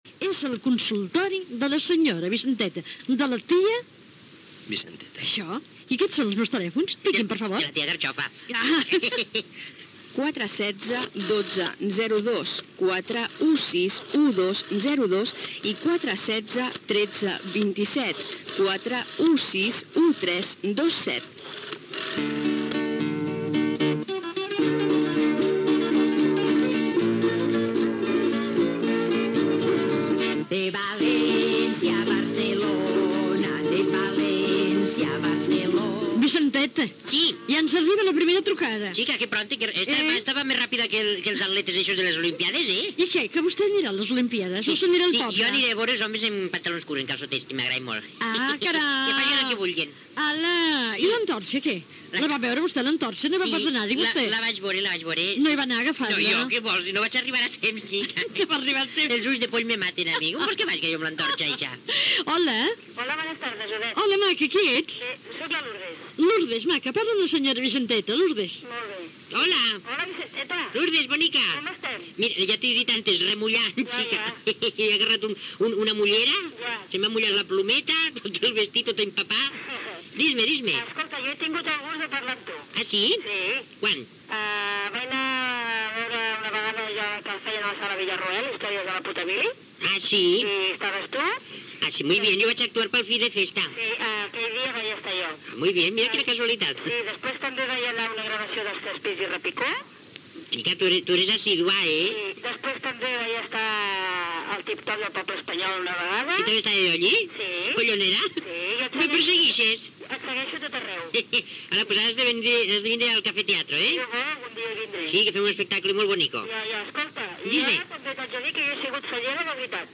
trucades telefòniques de l'audiència